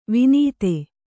♪ vinīte